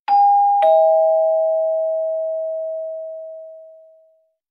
Doorbell.ogg